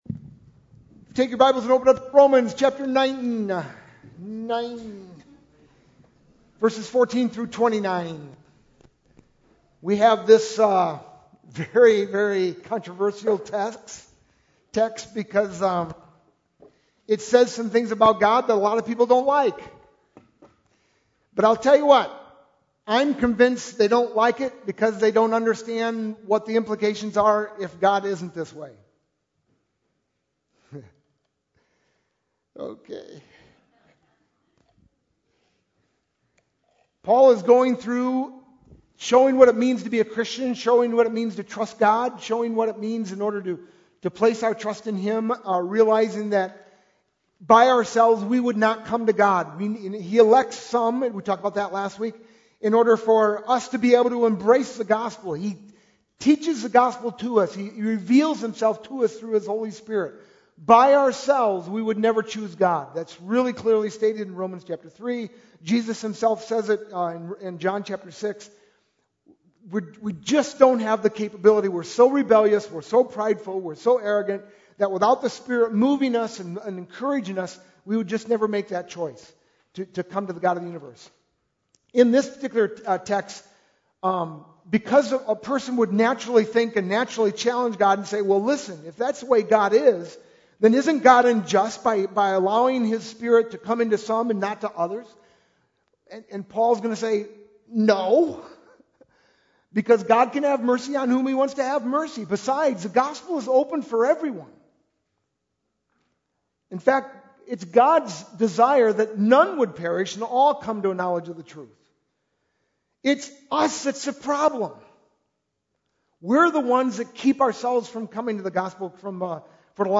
sermon-8-21-11.mp3